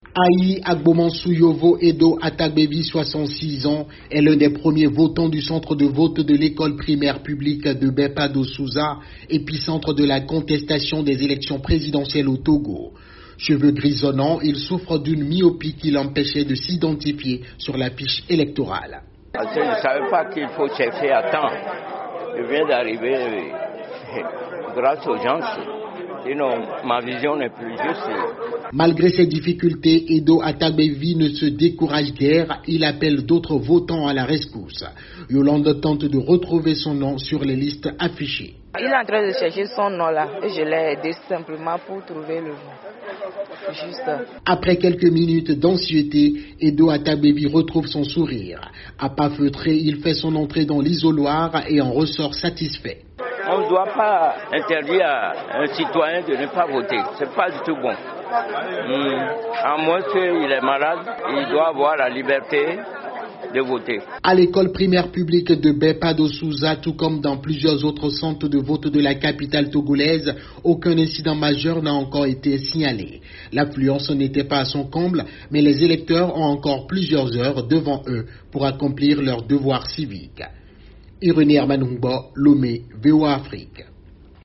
Reportage.